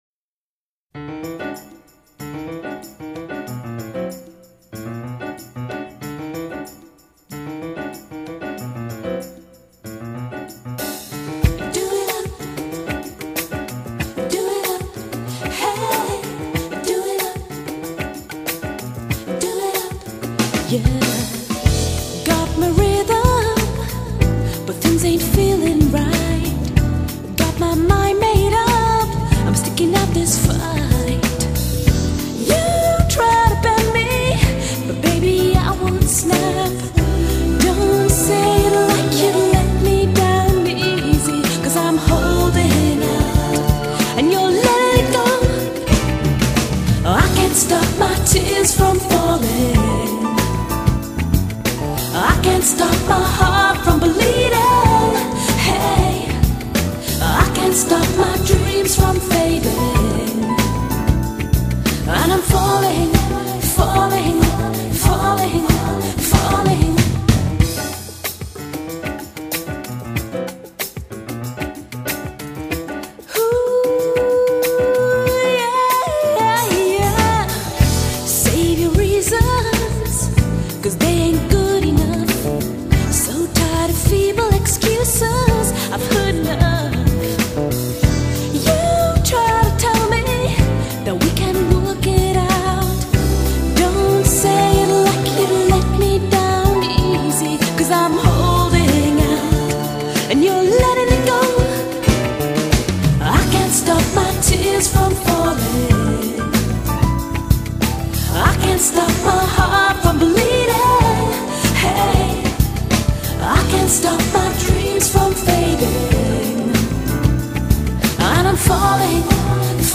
音樂類型 : 爵士
☆優雅的現代爵士風